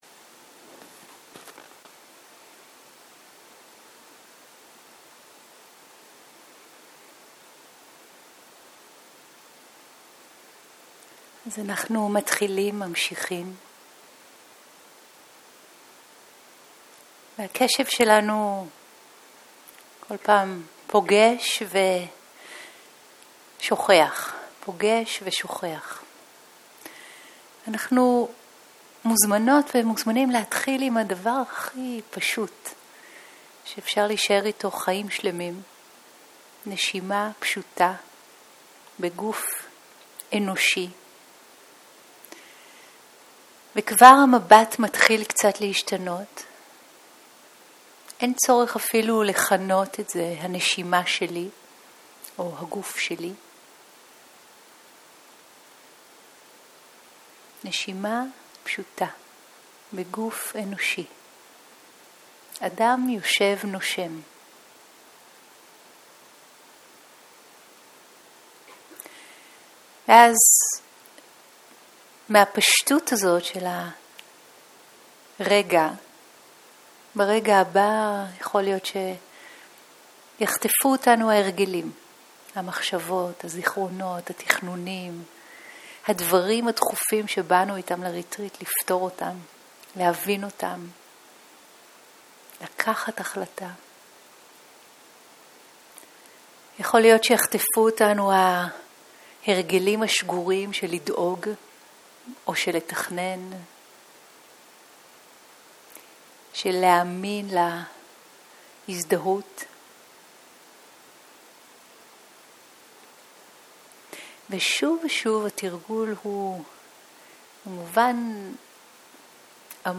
שיחת הנחיות למדיטציה שפת ההקלטה